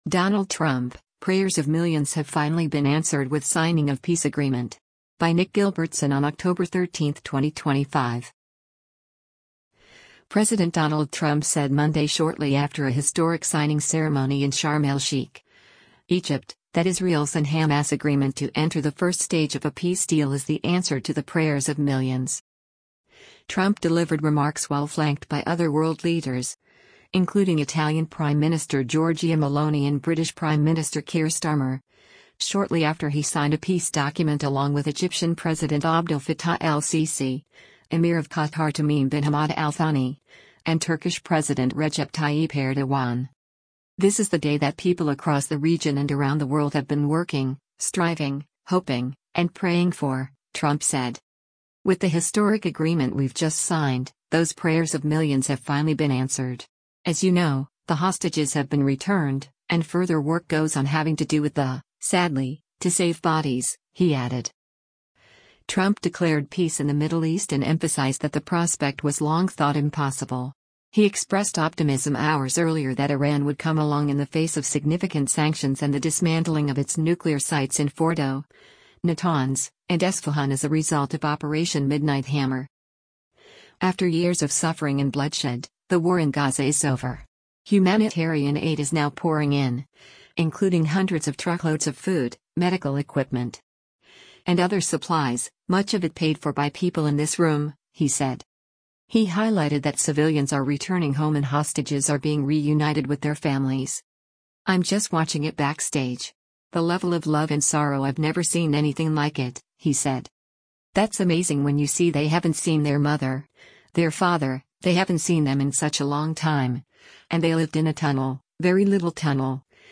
Trump delivered remarks while flanked by other world leaders, including Italian Prime Minister Giorgia Meloni and British Prime Minister Keir Starmer, shortly after he signed a peace document along with Egyptian President Abdel Fattah el-Sisi, Emir of Qatar Tamim bin Hamad Al Thani, and Turkish President Recep Tayyip Erdogan.